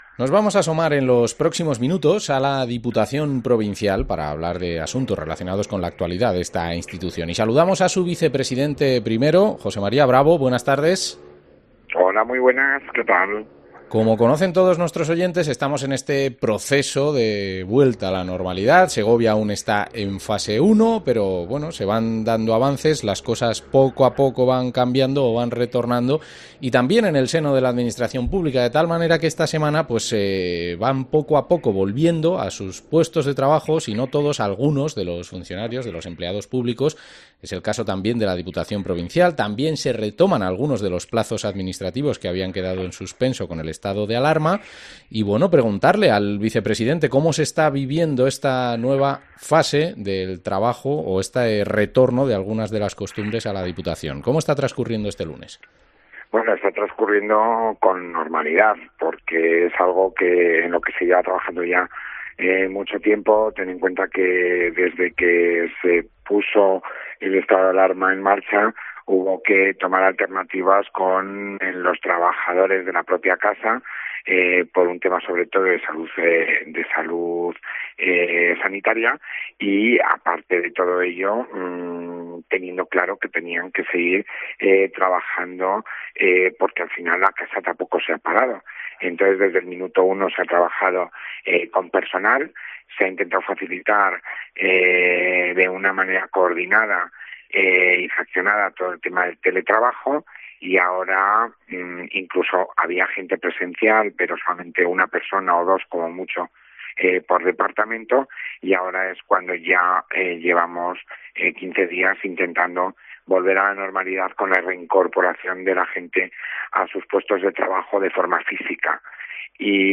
Entrevista al vicepresidente 1º de la Diputación, José María Bravo